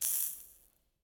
fake_fire_extinguish.2.ogg